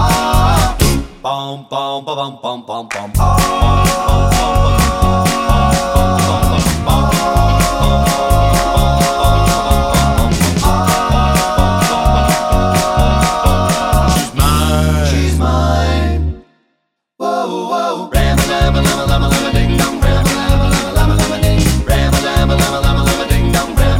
no Backing Vocals Rock 'n' Roll 2:20 Buy £1.50